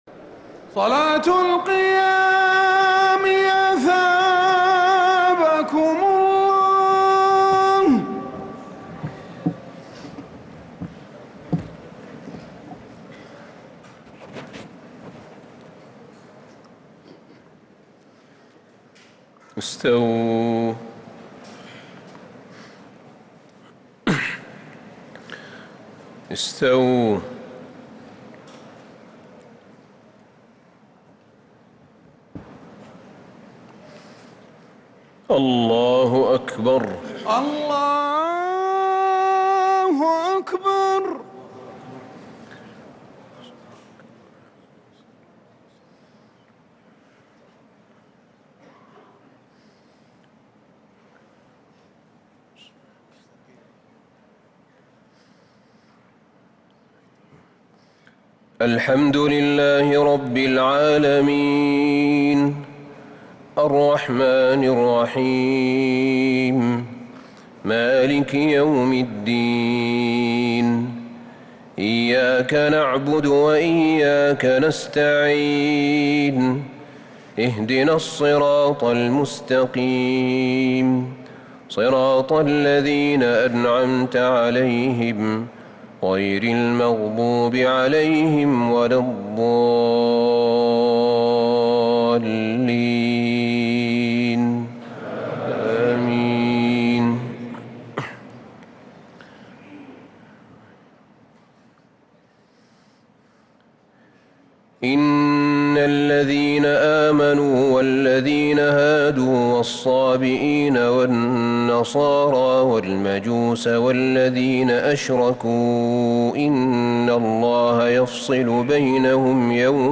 تراويح ليلة 22 رمضان 1443هـ من سورة الحج {17_ 78 } المؤمنون {1-22} | Taraweeh 22 th night Ramadan 1443H > تراويح الحرم النبوي عام 1443 🕌 > التراويح - تلاوات الحرمين